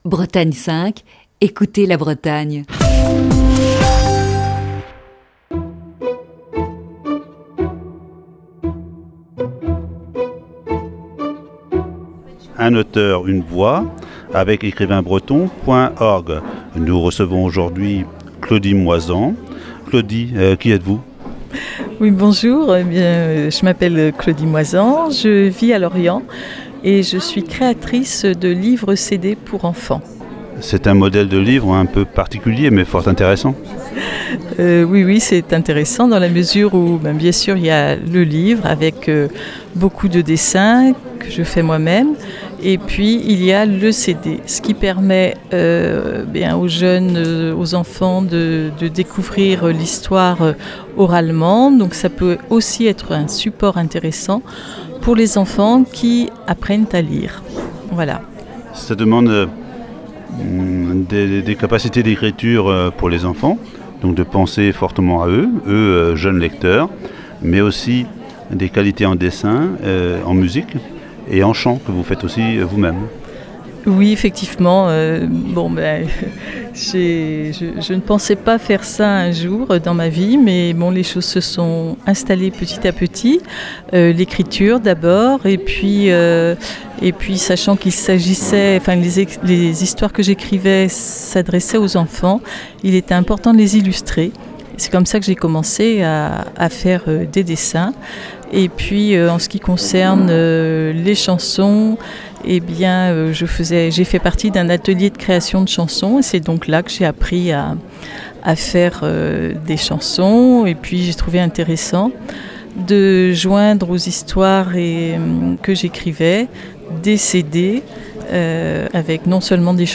(Entretien enregistré au Salon du livre de Plestin-les-Grèves).